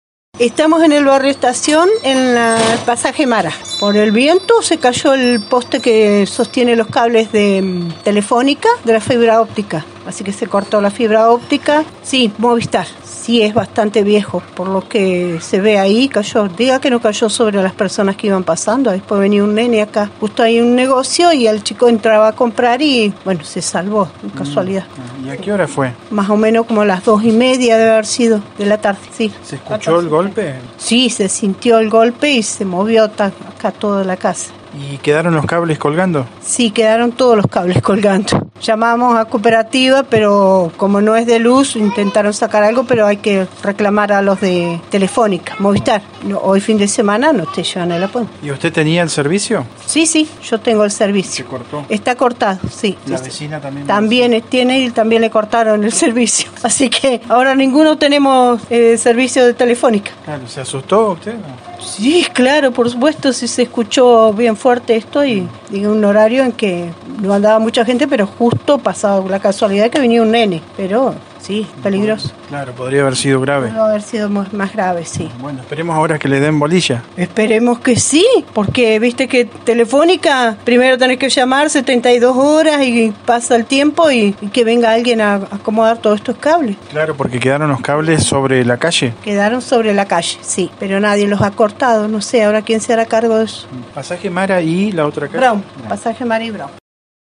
Además los cables quedaron tirados cruzando la calle y los usuarios sin servicio de fibra óptica. Noticias de Esquel fue el único medio en el lugar, donde conversamos con una vecina que relató que fue lo que sucedió.